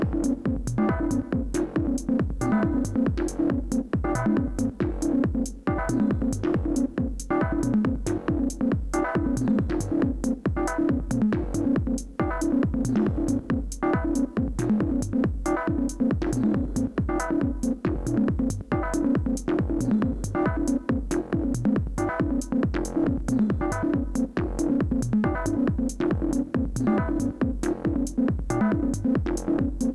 analog bass > TB303 like
fm sound > 2 notes
synth > detuned tri osc
distortion compression & eq in cubase
very bad recording with too much noise sorry this is done in 45min